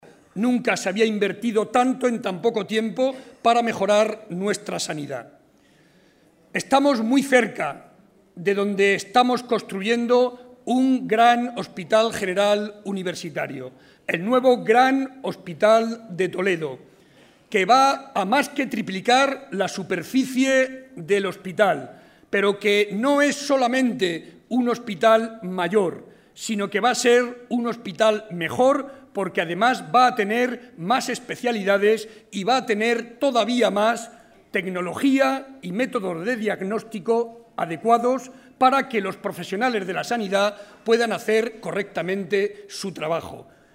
Audio Barreda mitin Toledo 3